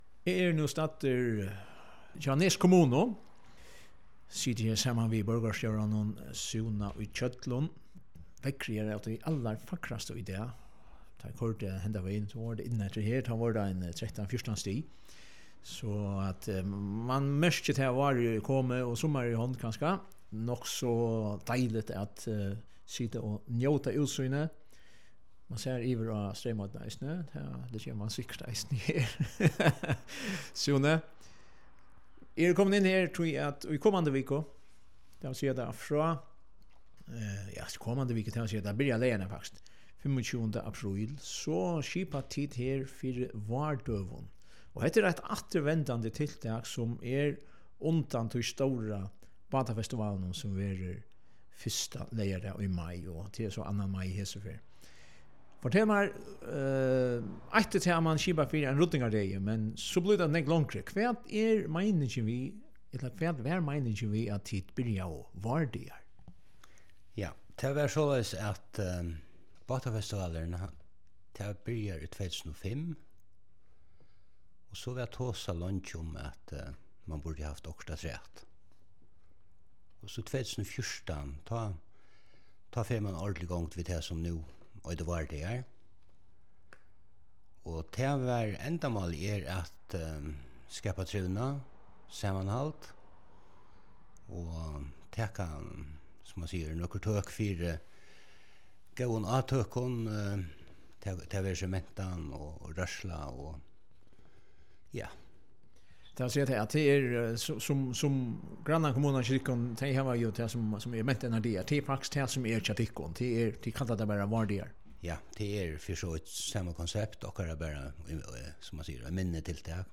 Súni í Hjøllum, borgarstjóri, greiðir her frá ymiskum, sum fer fram men greiðir eisini eitt sindur frá vøkstrinum í Nes Kommunu.
prátið